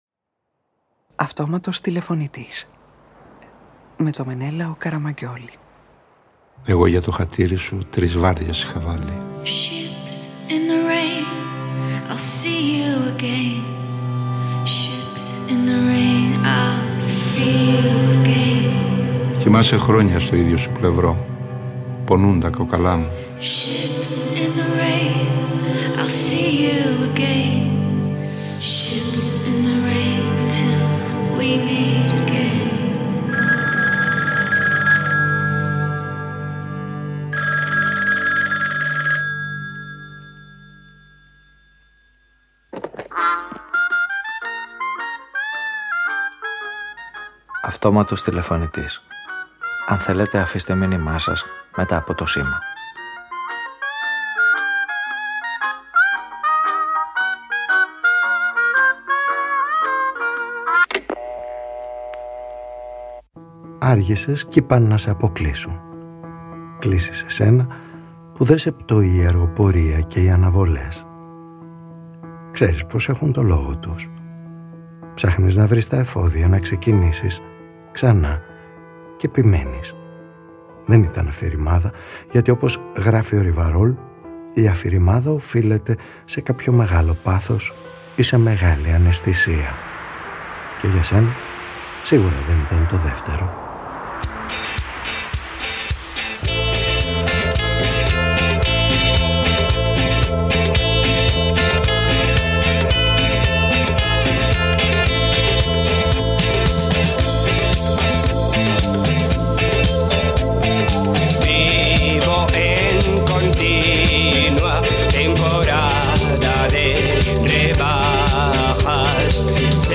Κλήση σε έναν ήρωα που δεν τον πτοούν η αργοπορία και οι αναβολές: οι δικές του και των άλλων σε μια ραδιοφωνική ταινία γεμάτη ήρωες που παλεύουν να βρουν αν μπορούν να ζήσουν μόνοι, ασφαλείς σε απομόνωση ή αν -όπως και να ‘χει- χρειάζονται τους άλλους για να προχωρήσουν.